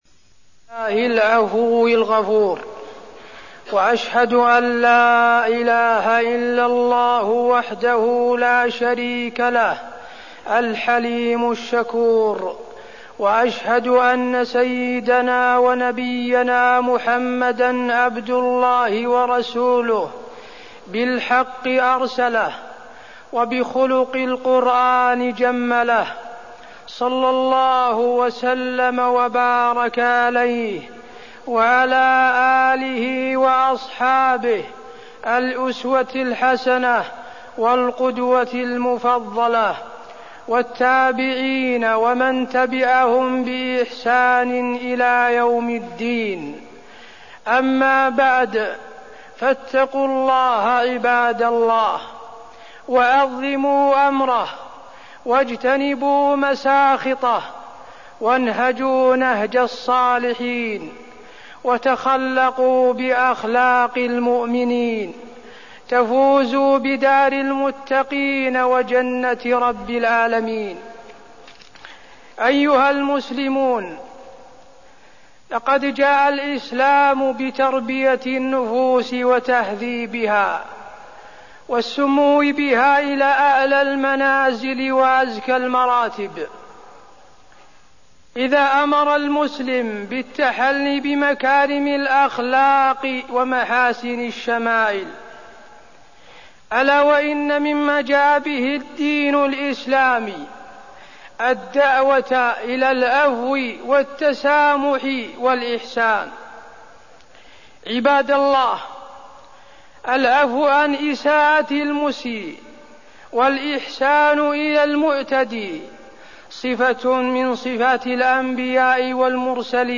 تاريخ النشر ١٨ صفر ١٤١٩ هـ المكان: المسجد النبوي الشيخ: فضيلة الشيخ د. حسين بن عبدالعزيز آل الشيخ فضيلة الشيخ د. حسين بن عبدالعزيز آل الشيخ الحث على مكارم الاخلاق